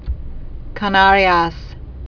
(kä-näryäs), Islas